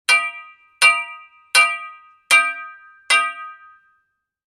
Лом ударяет по краю бетонного блока слышен звук